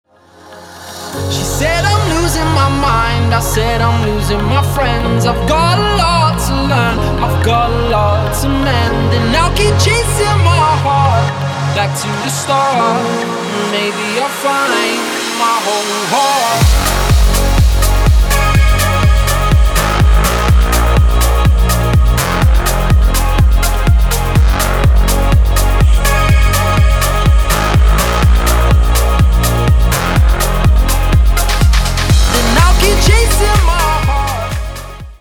• Качество: 320, Stereo
мужской вокал
dance
Electronic
EDM
Tech House
Bass
Стиль: Tech house.